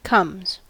Ääntäminen
Ääntäminen US : IPA : [kʌmz] Haettu sana löytyi näillä lähdekielillä: englanti Käännöksiä ei löytynyt valitulle kohdekielelle.